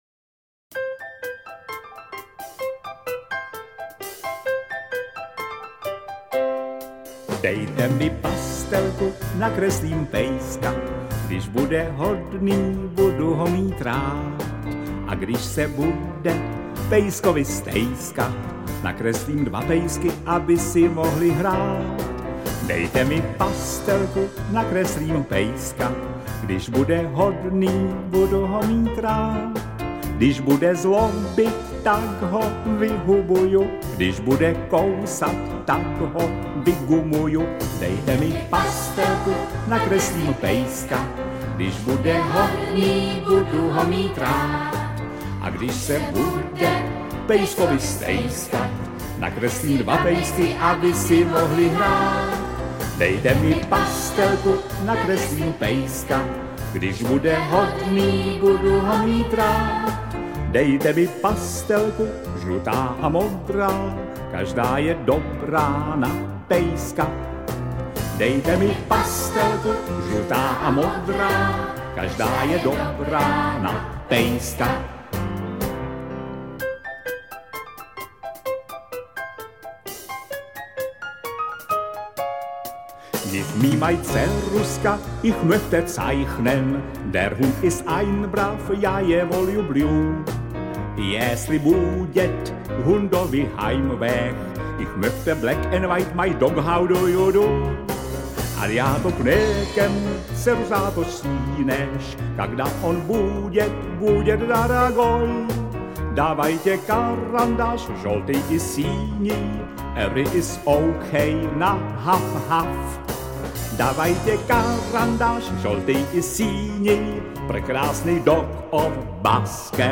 Písničky pro děti